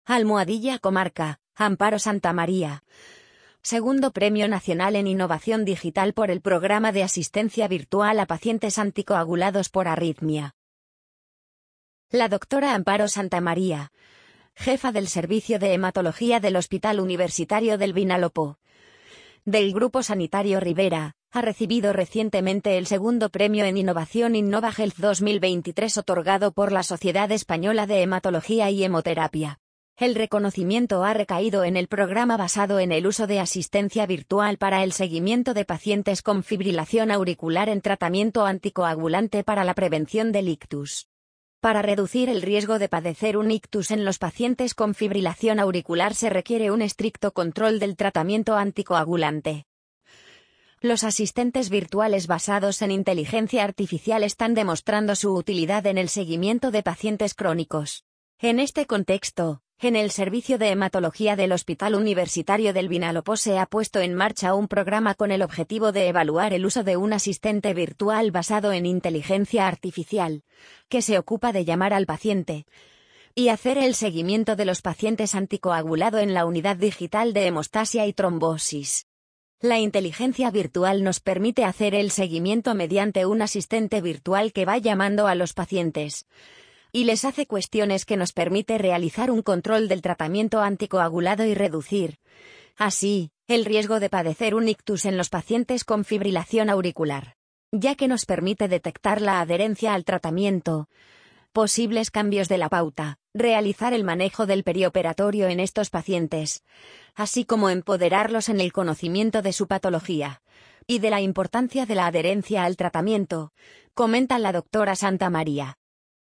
amazon_polly_69548.mp3